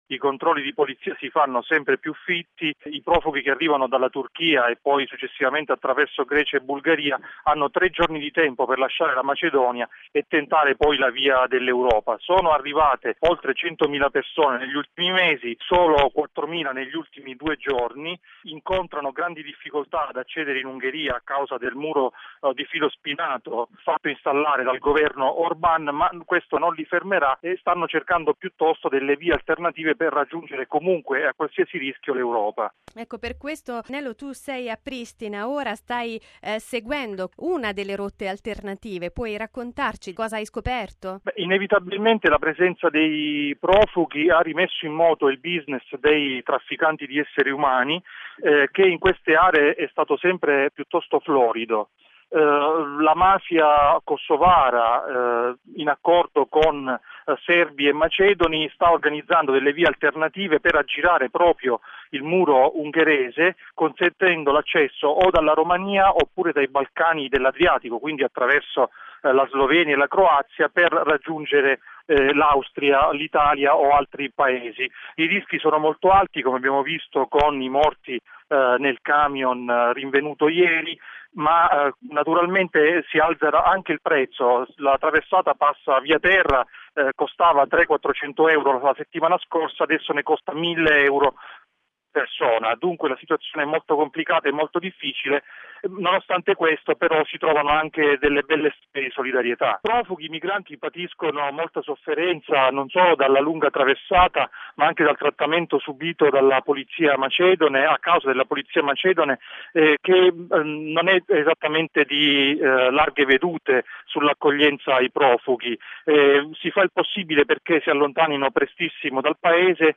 Intanto, al largo della Libia l’affondamento di due barconi ha provocato almeno 200 morti, mentre altre decine di migranti hanno perso la vita per asfissia in un tir in Austria. Il servizio